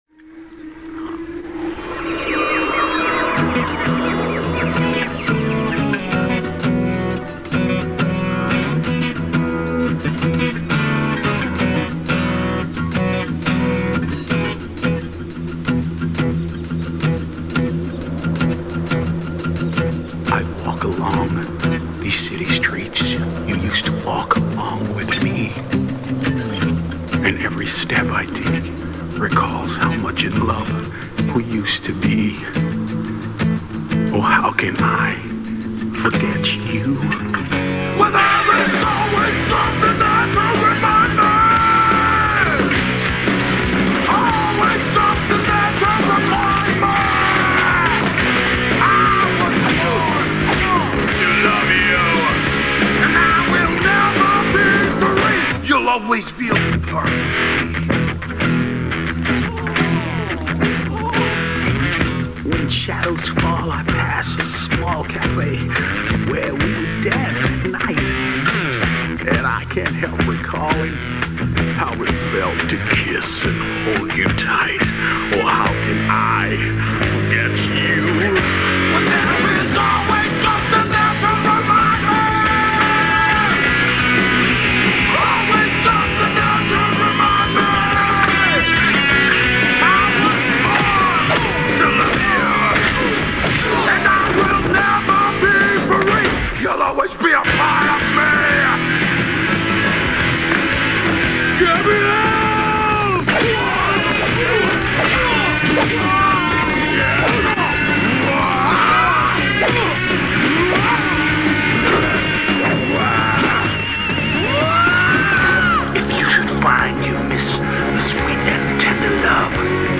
Все песни записаны с видео, без русского перевода.